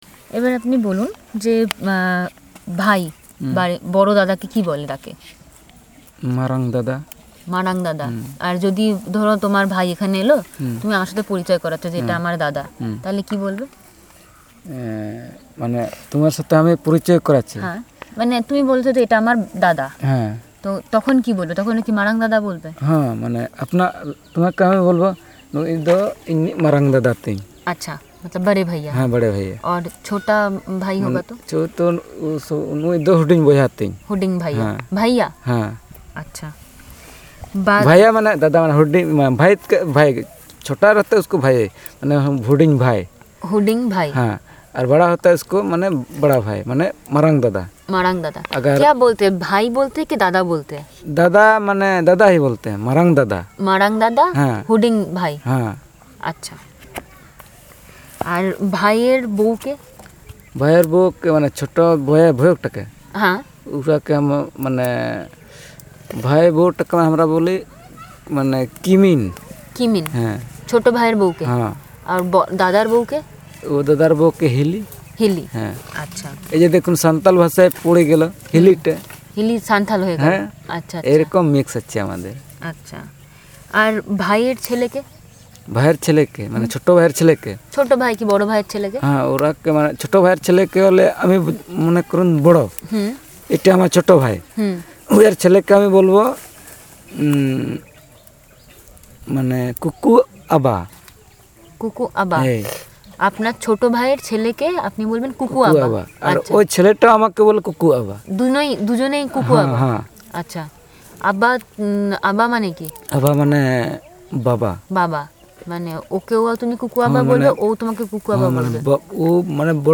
Elicitation of words related to kinship terms